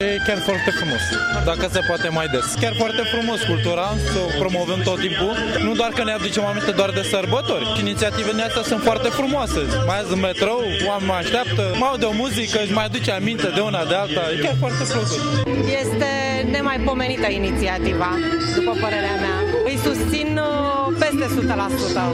calatori.mp3